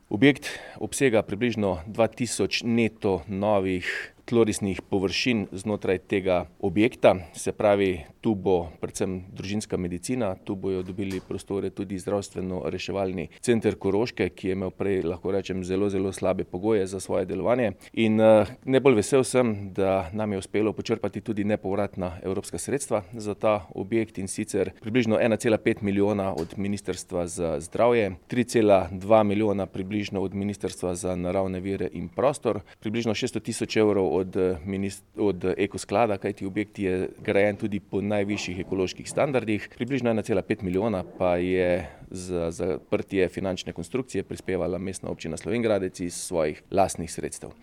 Slovenjgraški župan Tilen Klugler: